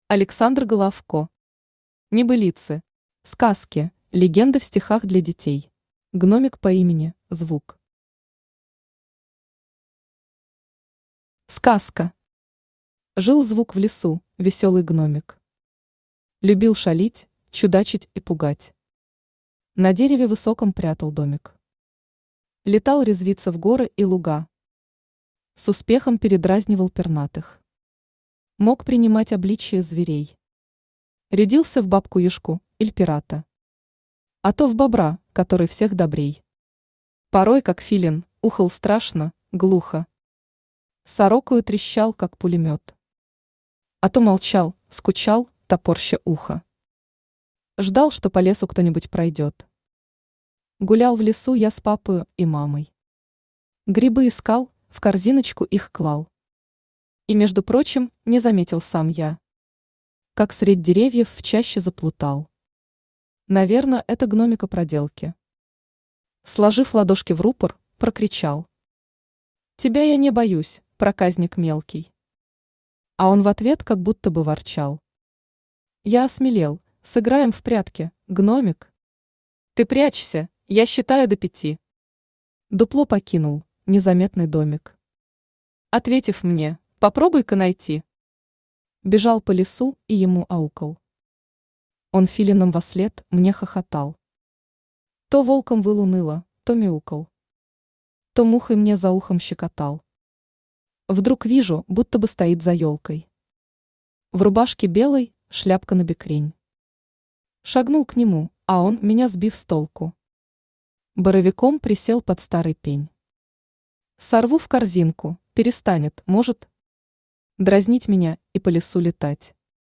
Аудиокнига Небылицы. Сказки, легенды в стихах для детей | Библиотека аудиокниг
Сказки, легенды в стихах для детей Автор Александр Власович Головко Читает аудиокнигу Авточтец ЛитРес.